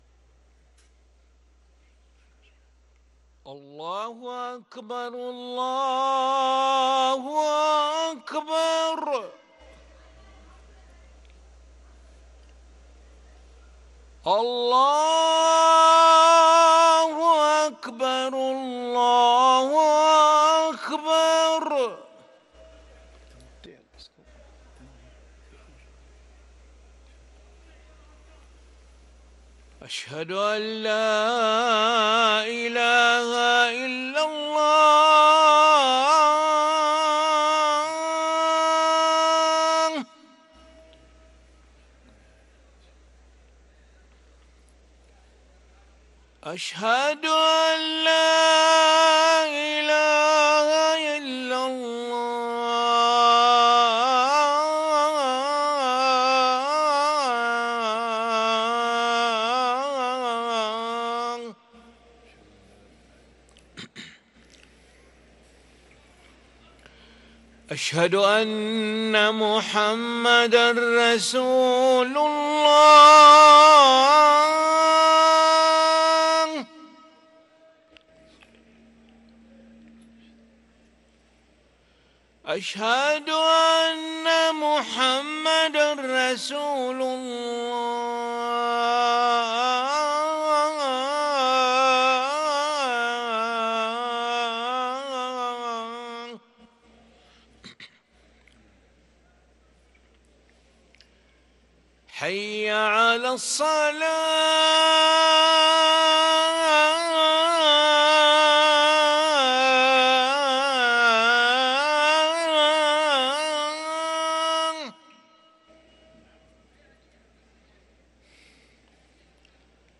أذان العشاء للمؤذن علي ملا الأحد 15 جمادى الآخرة 1444هـ > ١٤٤٤ 🕋 > ركن الأذان 🕋 > المزيد - تلاوات الحرمين